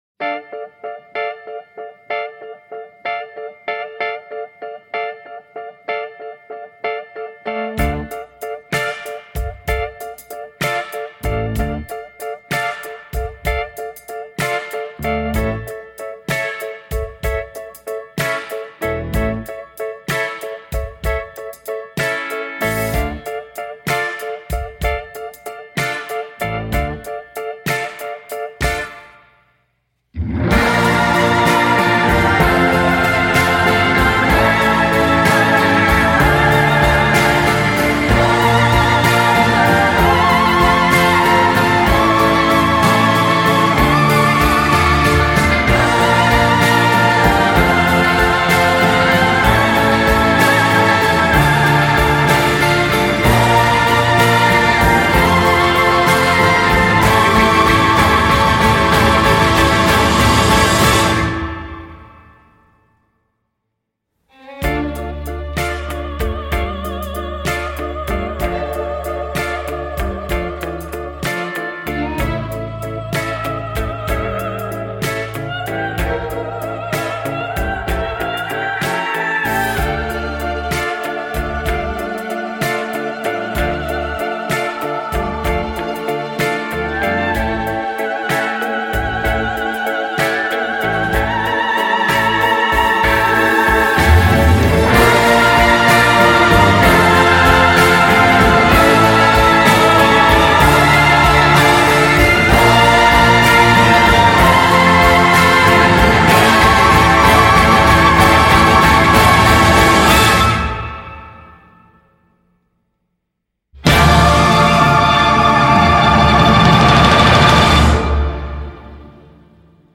La musique :  parfois à l’avenant, bruyante et hystérique.
on navigue du rockabilly au hard 80’s